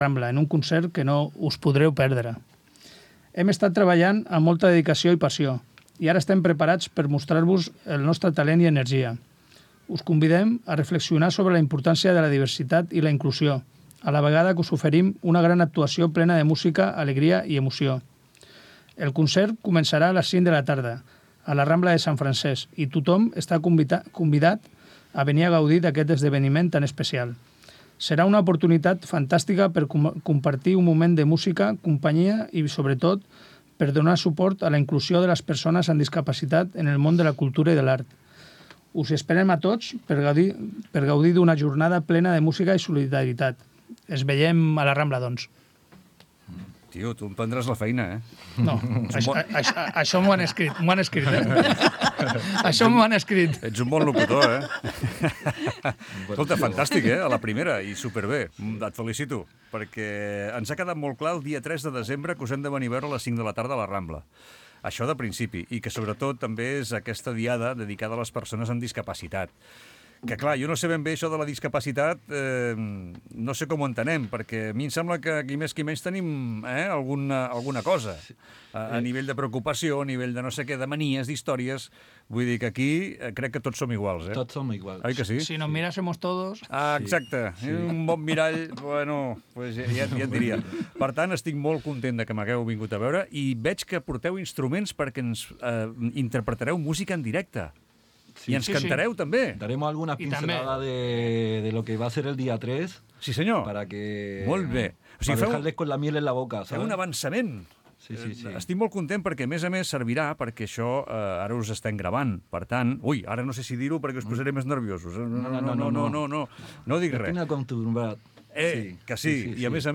Entrevista als Acabamos de llegar 27/6/25